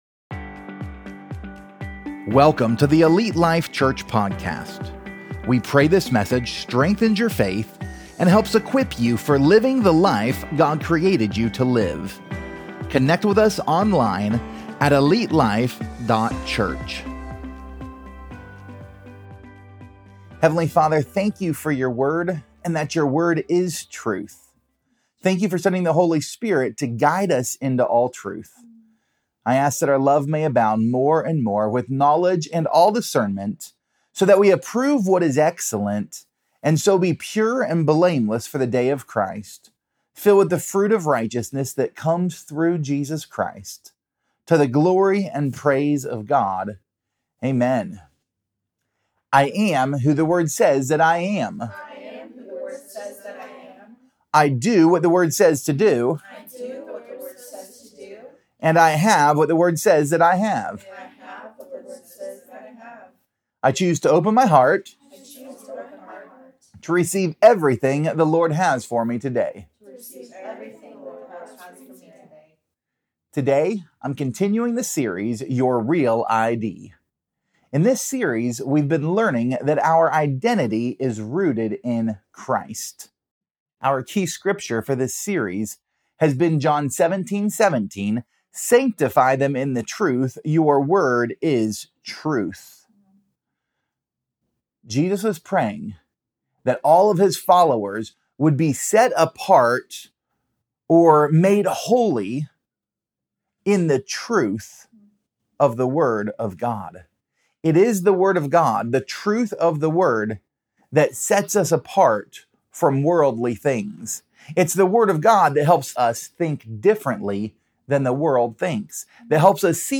Pt 18: Healed | Your REAL ID Sermon Series